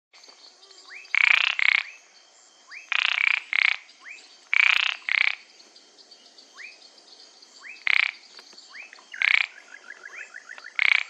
Swimming Frog (Pseudis platensis)
Detailed location: camping municipal
Condition: Wild
Certainty: Recorded vocal
pseudis-platensis.mp3